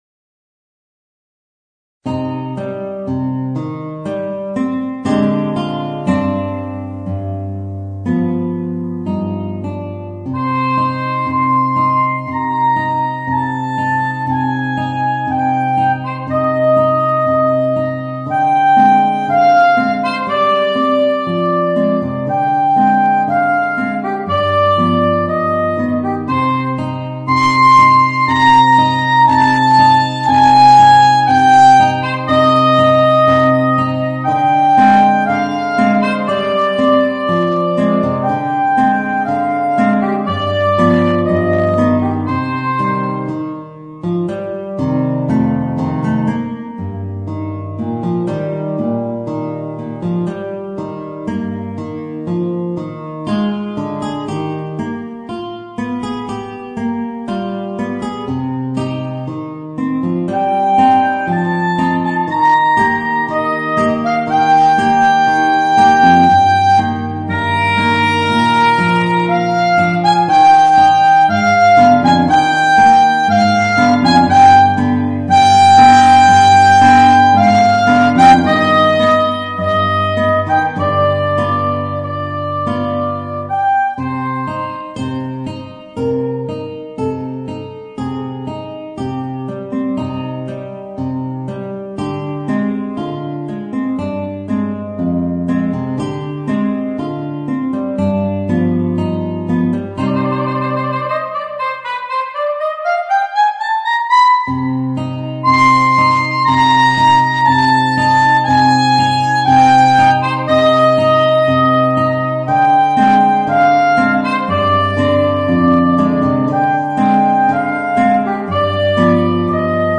Voicing: Soprano Saxophone and Guitar